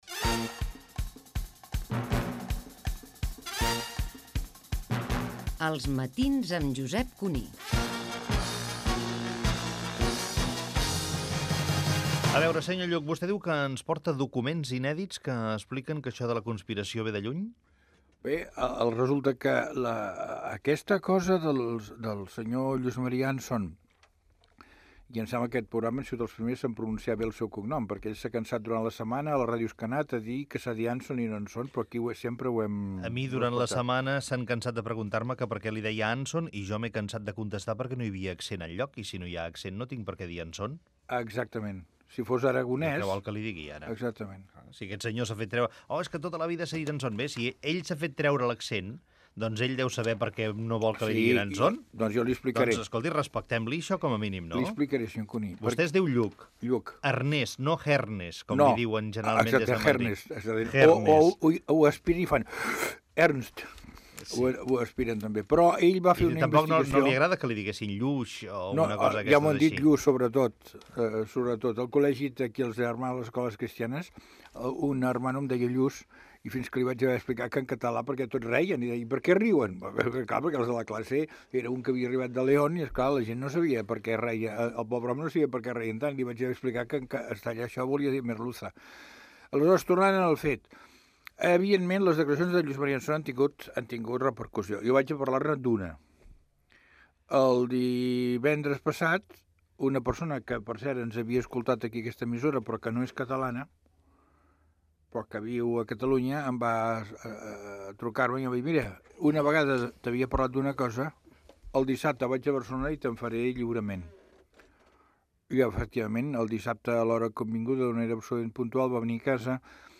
c38493f12fdb06fd3e31c12e08a7a7e3ca025fcf.mp3 Títol COM Ràdio Emissora COM Ràdio Barcelona Cadena COM Ràdio Titularitat Pública nacional Nom programa Els matins amb Josep Cuní Descripció Indicatiu del programa, secció "Foc creuat" entre Ernest Lluch i Baltasar Porcel, sobre la teoria de la conspiració periodística, política i financera. Gènere radiofònic Info-entreteniment